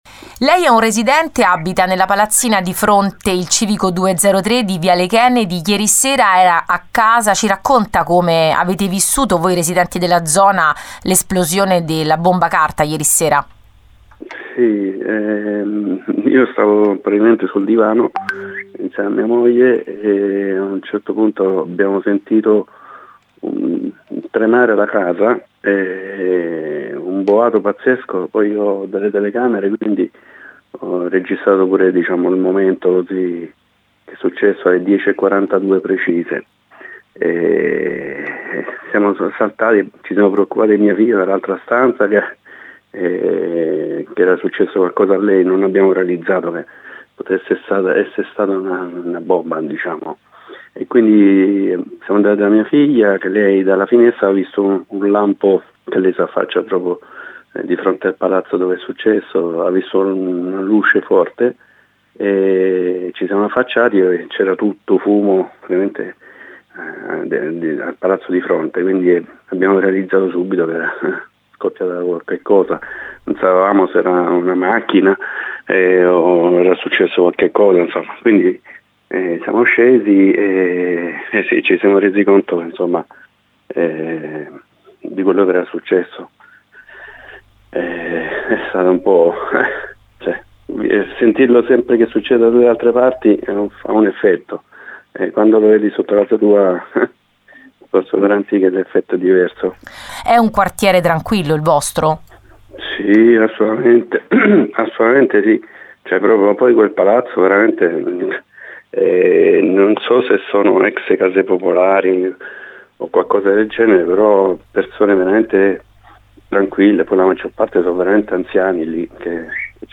Uno dei residenti della palazzina di fronte all’edificio colpito, che in quel momento era in casa con la famiglia, racconta quei minuti concitati: